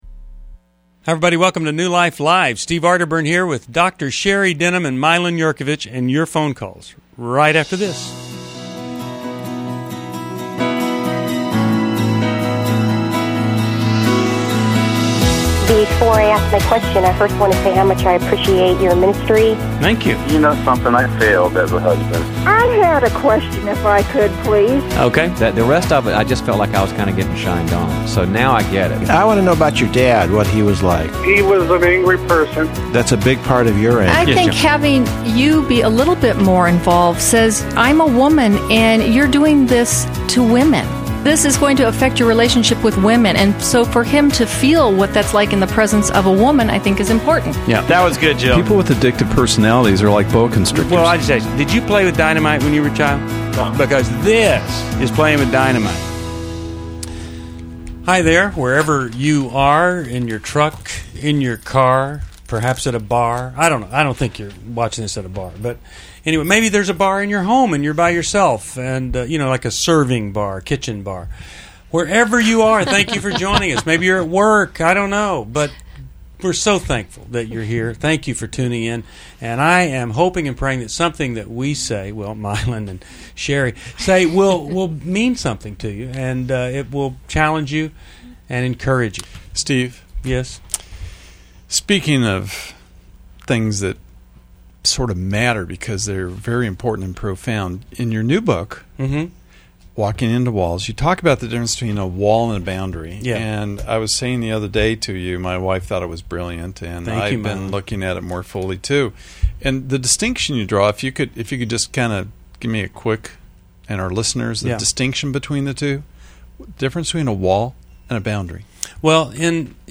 Explore relationship challenges on New Life Live: August 30, 2011, as callers tackle boundaries, separation, divorce, and PTSD recovery.